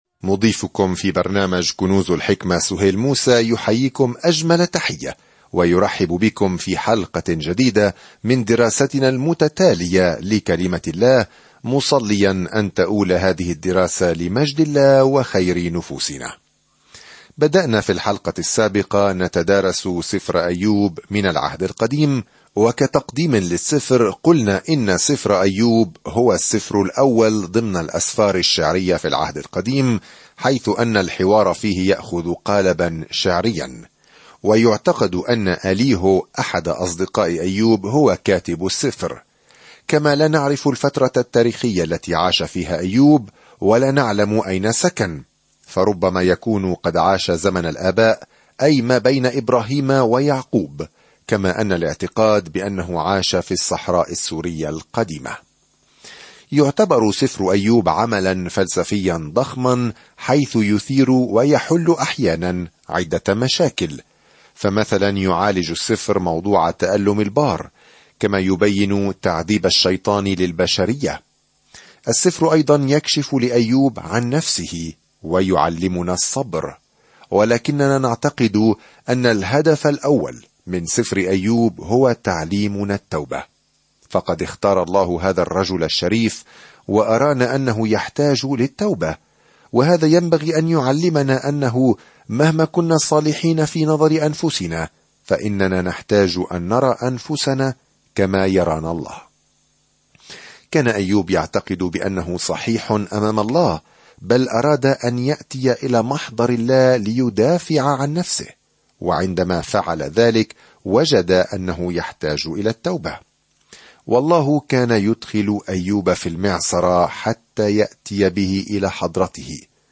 الكلمة أَيُّوبَ 6:1-22 يوم 1 ابدأ هذه الخطة يوم 3 عن هذه الخطة في دراما السماء والأرض هذه، نلتقي بأيوب، الرجل الصالح، الذي سمح الله للشيطان أن يهاجمه؛ كل شخص لديه الكثير من الأسئلة حول سبب حدوث الأشياء السيئة. سافر يوميًا عبر أيوب وأنت تستمع إلى الدراسة الصوتية وتقرأ آيات مختارة من كلمة الله.